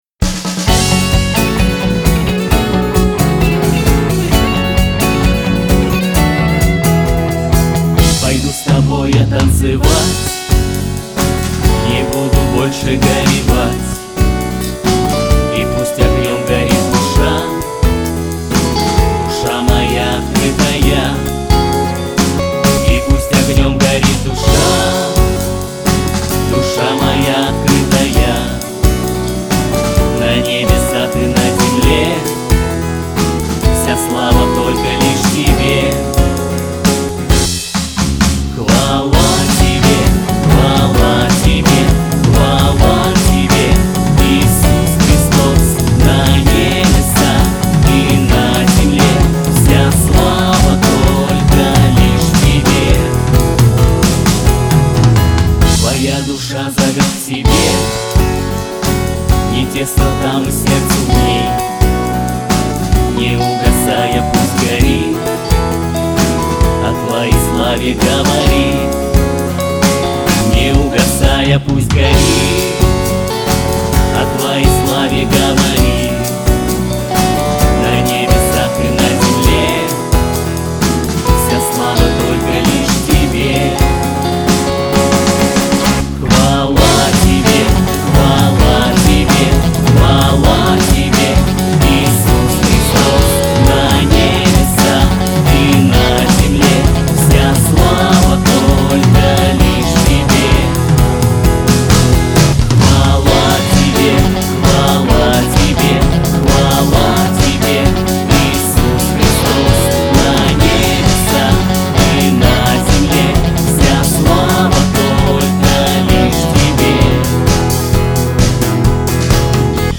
58 просмотров 102 прослушивания 5 скачиваний BPM: 134